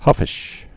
(hŭfĭsh)